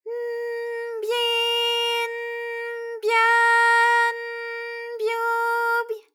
ALYS-DB-001-JPN - First Japanese UTAU vocal library of ALYS.
by_n_byi_n_bya_n_byu_.wav